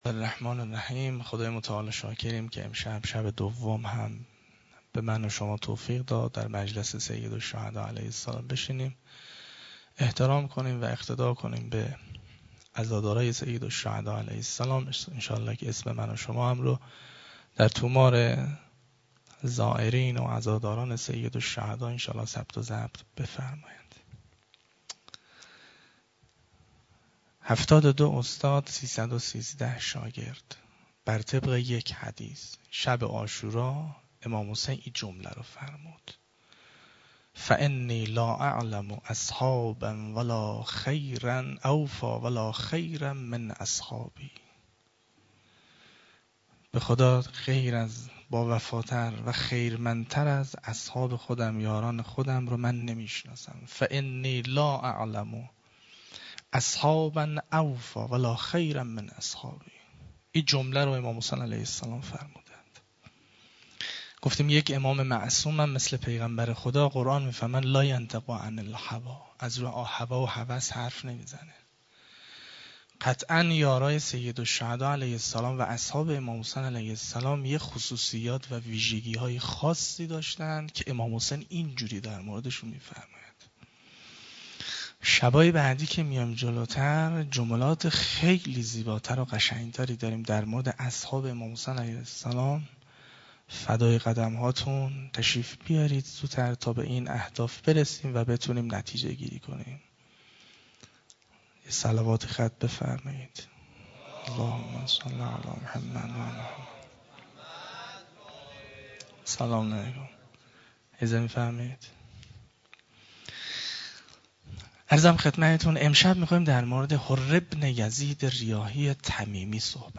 خیمه گاه - هیئت دانشجویی فاطمیون دانشگاه یزد - سخنرانی
شب دوم محرم ۹۵